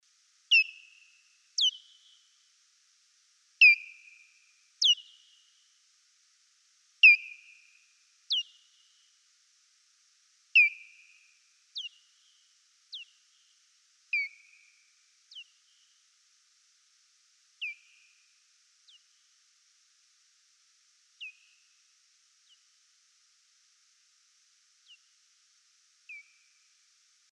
Genre: Animal Sound Recording.
2109_Abendsegler_Ortungslaute_short.mp3